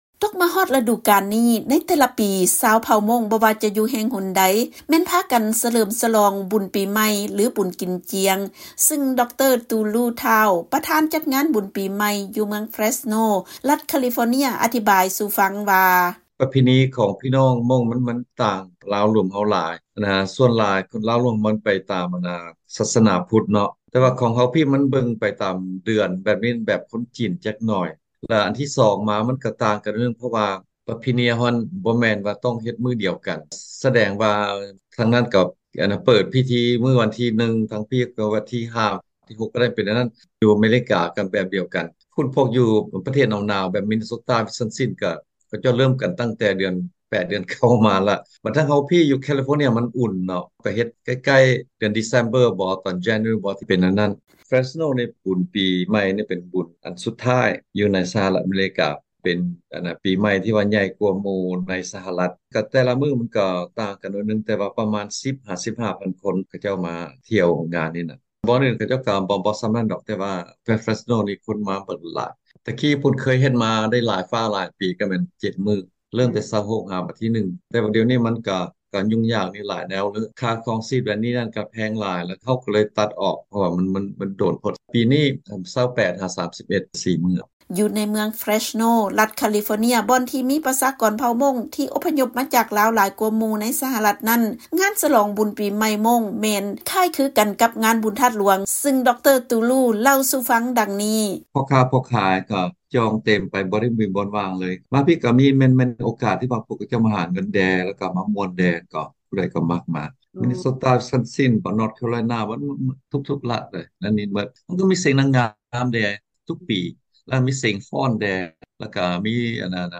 ຟັງລາຍງານ ການສະຫລອງປີໃໝ່ຂອງພີ່ນ້ອງຊາວມົ້ງ ຢູ່ໃນສະຫະລັດ ແລະປະເທດກີຢານາ ຝຣັ່ງ